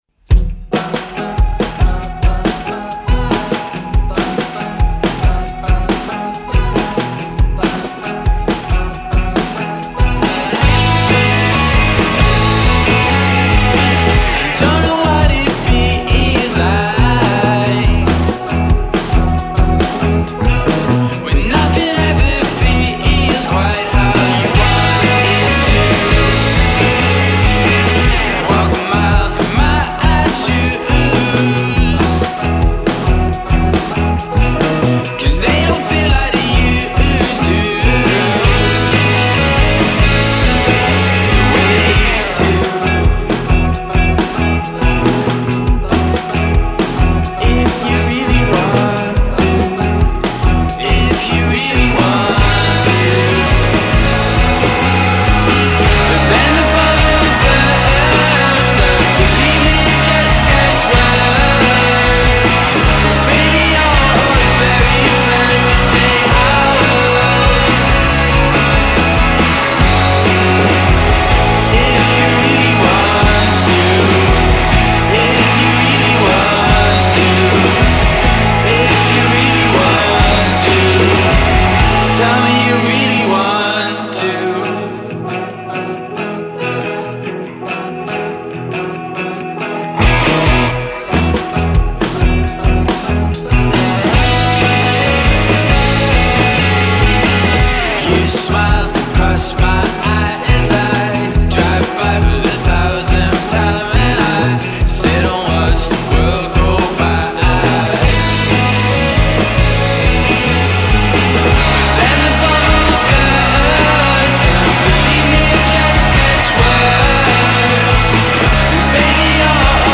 Newcastle six-piece